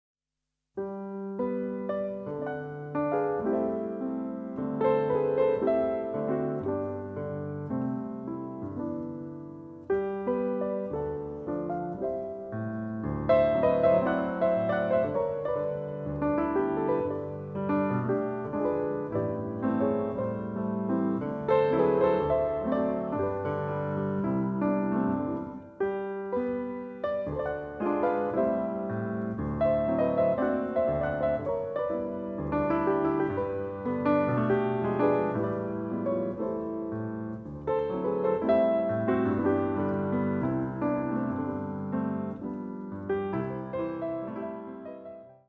いわゆる「イージーリスニング」と呼ばれる、1920〜50年代にヒットした曲を中心とした選曲。
ハワイに行ったことがある人もない人も、優しい海風のようなこのピアノに、ただ身を任せてください。